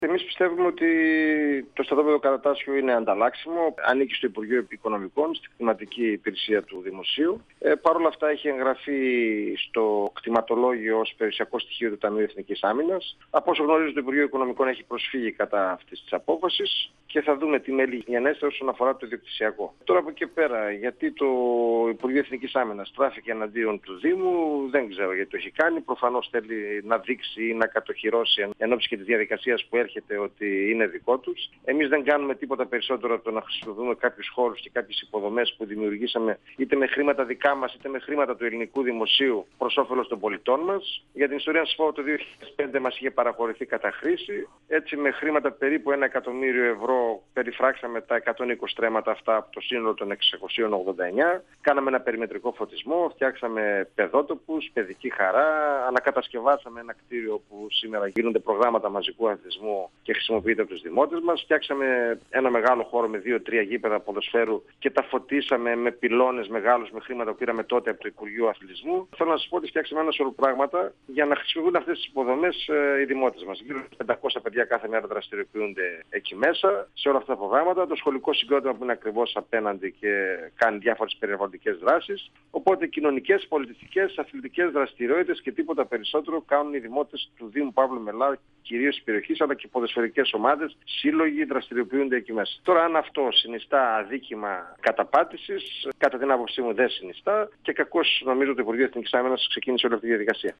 Ο δήμαρχος Παύλου Μελά Δημήτρης Δεμουρτζίδης, στον 102FM του Ρ.Σ.Μ. της ΕΡΤ3
Συνέντευξη